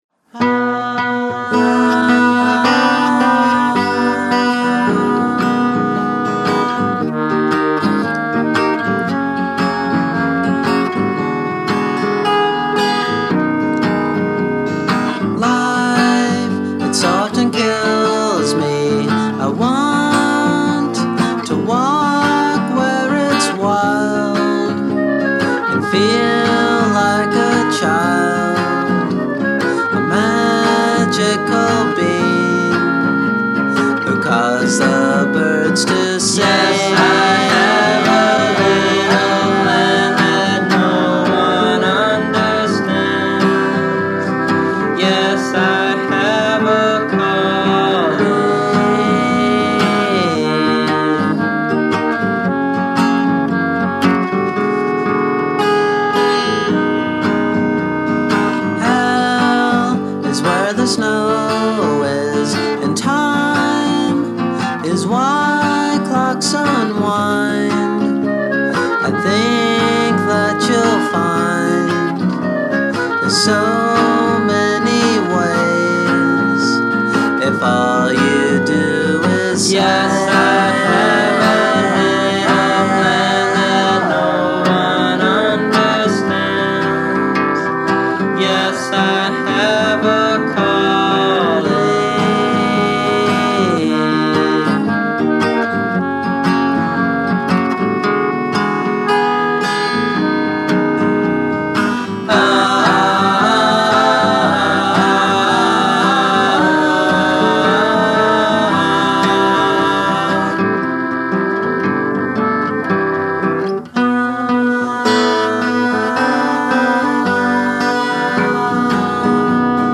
se convierten en especiales ejercicios de poesía folk